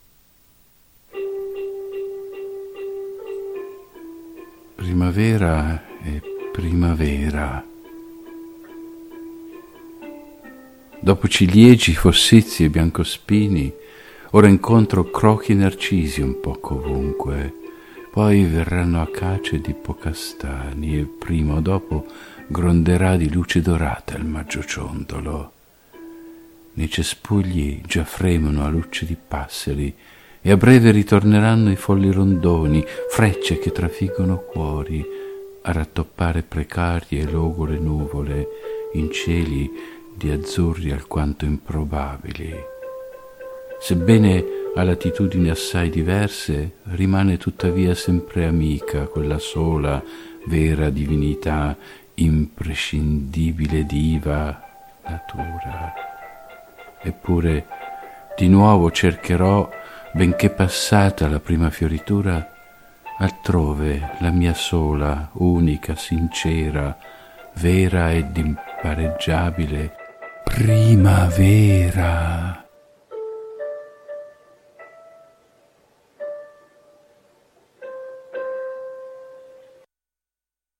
Il sottofondo musicale è “Primavera”, un pezzo originale,